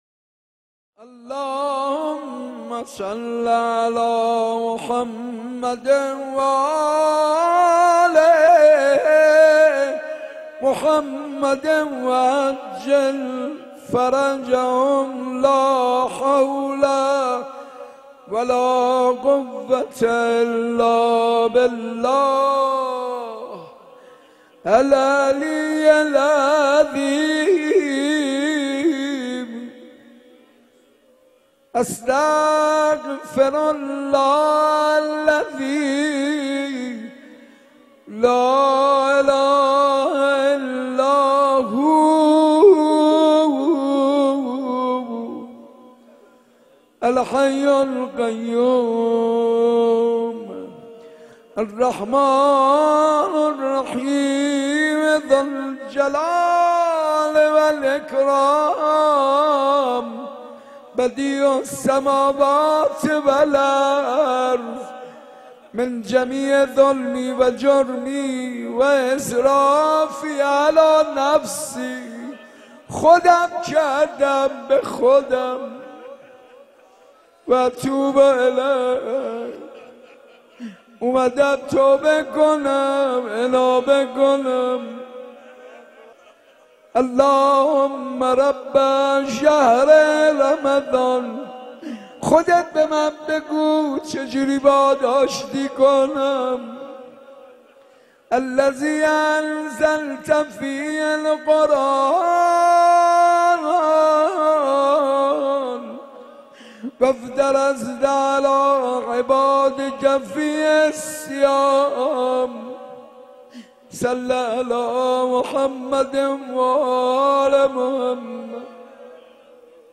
مراسم مناجات شب دوازدهم ماه مبارک رمضان با سخنرانی حجت الاسلام و المسلمین سیدمهدی میرباقری و مناجات حاج منصور ارضی با حضور پرشور مردم مومن و شب زنده دار در مسجد ارک تهران برگزار گردید.